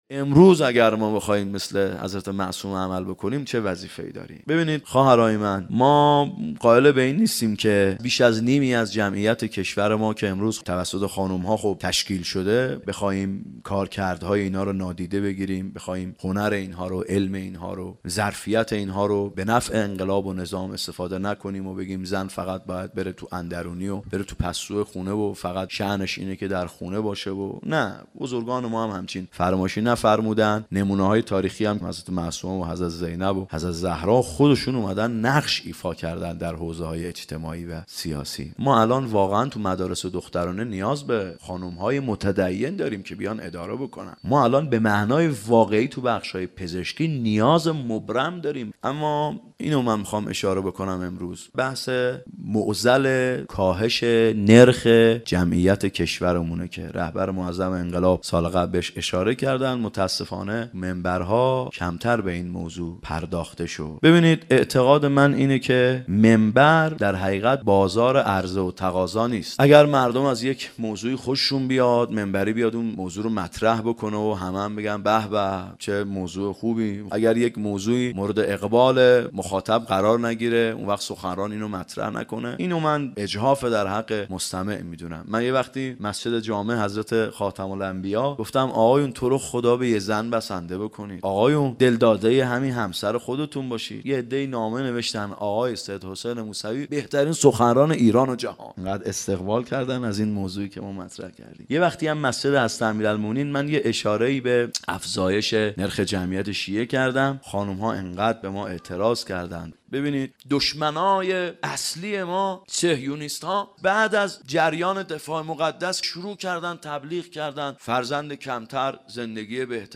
راه بی پایان سخنرانی 93